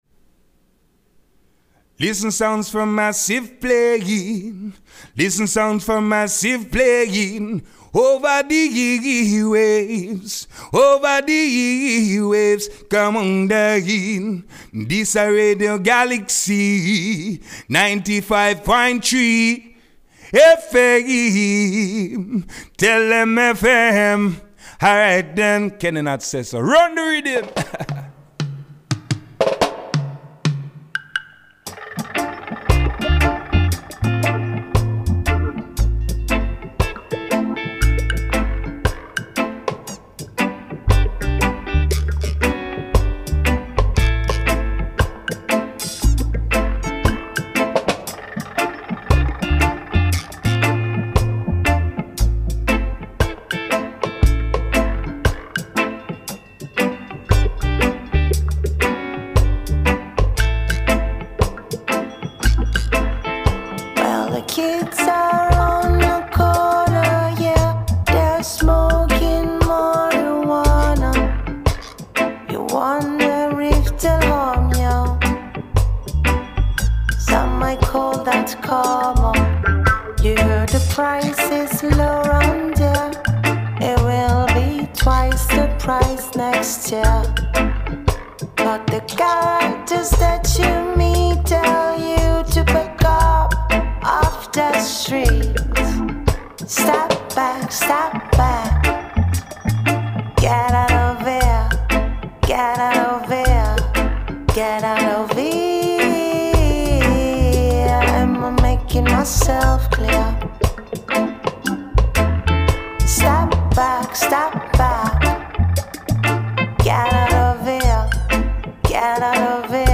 Sound 4 Massive - le REPLAY retrouvez votre rendez-vous reggaephonique animé par I Bingi Sound et enregistré lundi 12 janvier 2026 dans les studios de Galaxie Radio Tracklist du soir : 1.